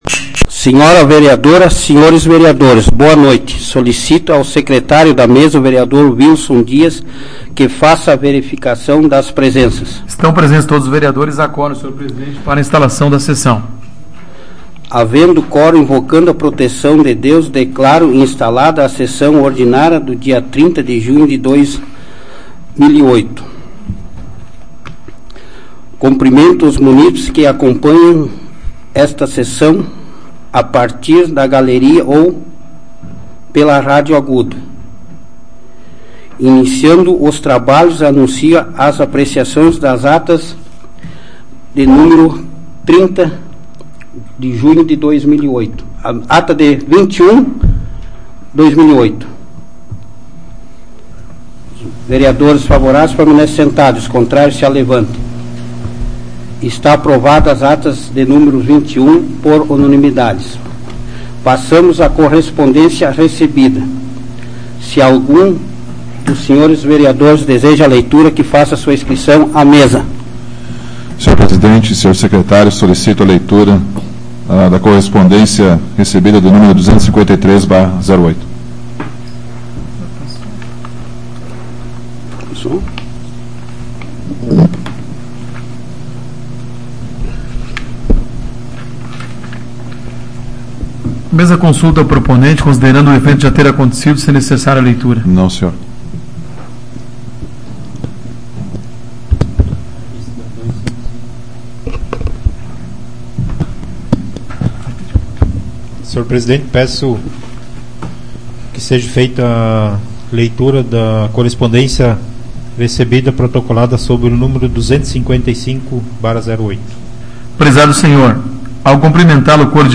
Áudio da 128ª Sessão Plenária Ordinária da 12ª Legislatura, de 30 de junho de 2008